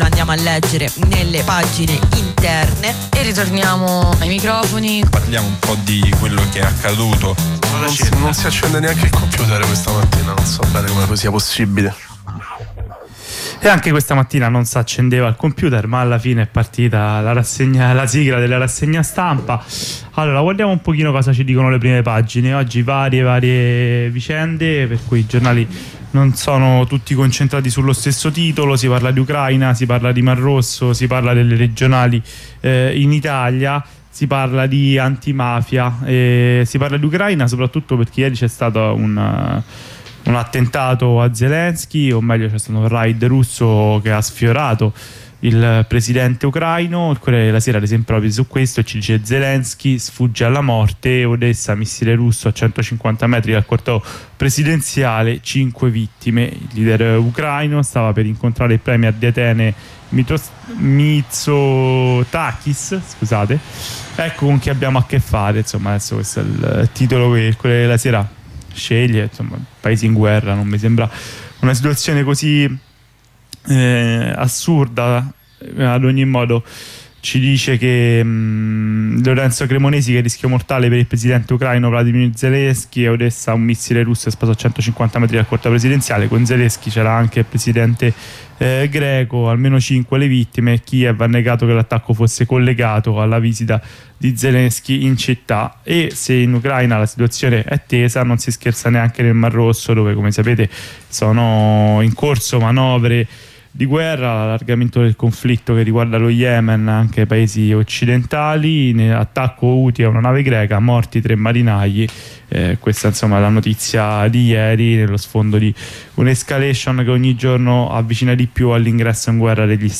La lettura dei giornali di oggi